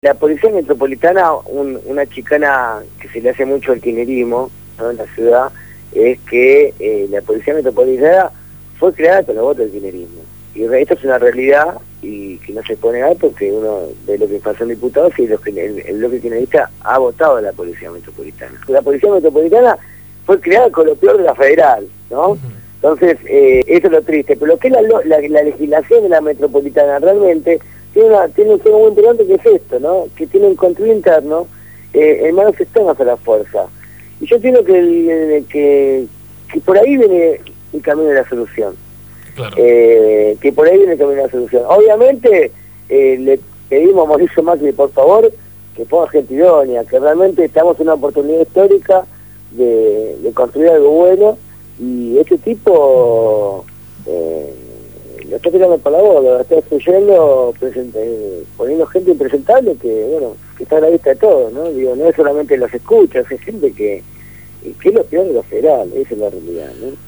Hoy fue entrevistado